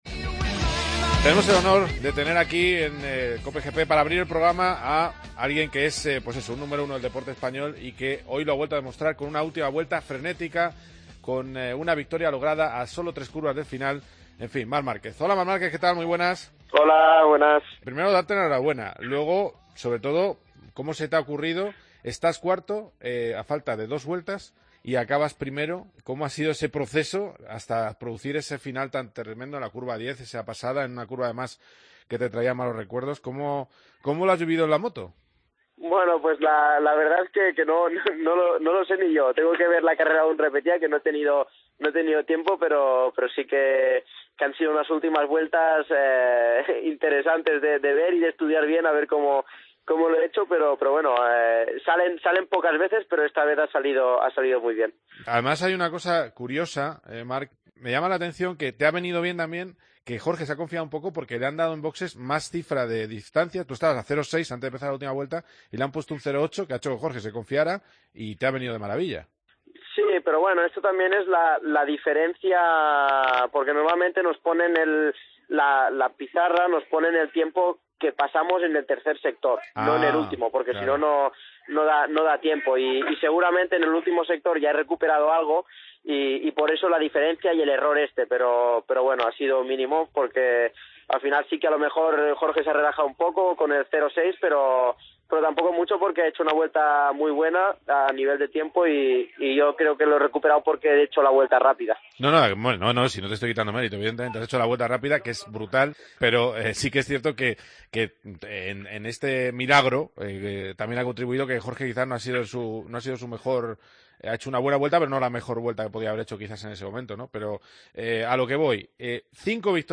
Entrevista a Marc Márquez, en COPE GP